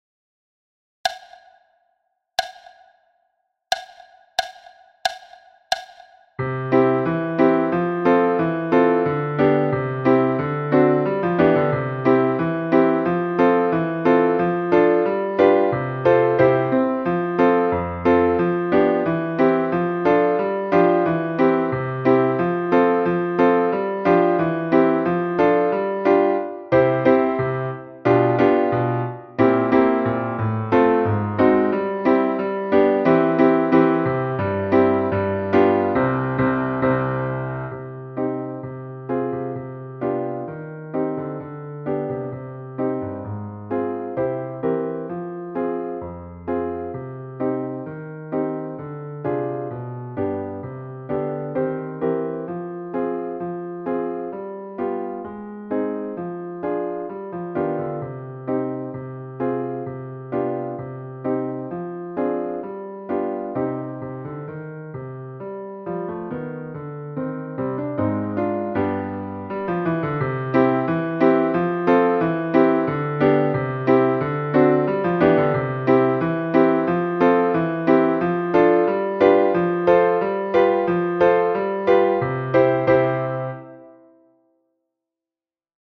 Après la pluie – piano à 90 bpm
Apres-la-pluie-piano-a-90-bpm.mp3